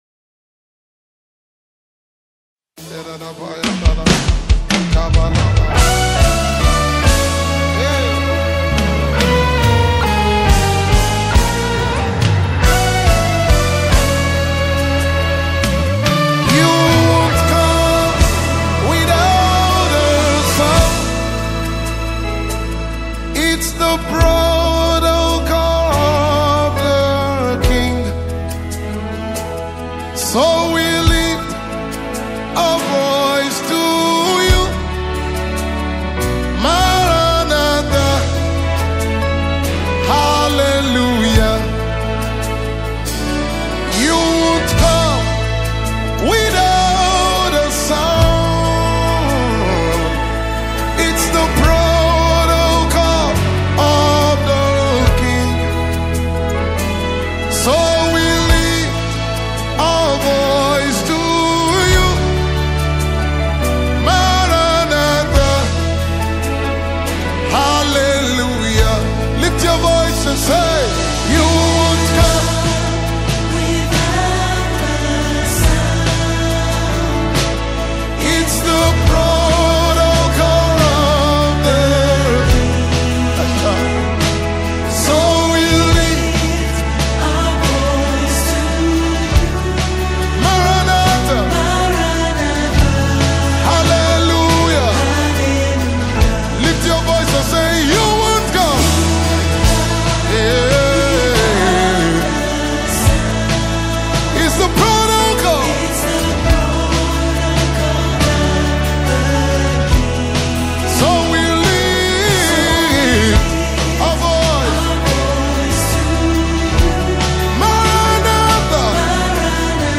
Nigerian Gospel Music